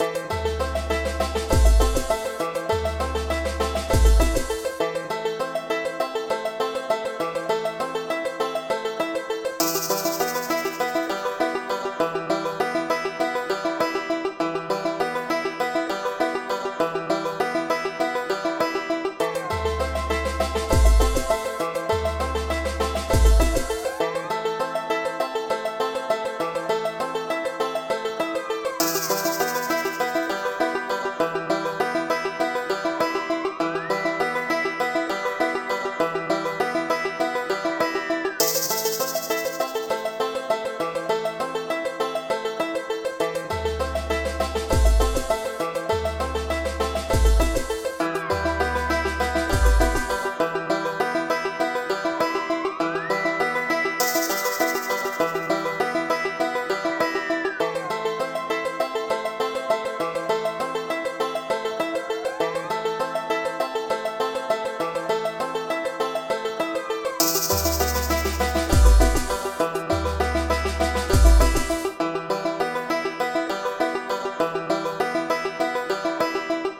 Aktuell arbeite ich an den Ideen für die verschiedenen Level*, importiere und organisiere die assets und komponiere ein wenig hintergrund-loops.
Als Software verwende ich den MusicMaker und ein Keyboard in Verbindung mit VST-Plugins (im MM).
Dabei suche ich zuerst nach passenden soundloops im pool und füge teils noch selbst aufgenommene loops dazu.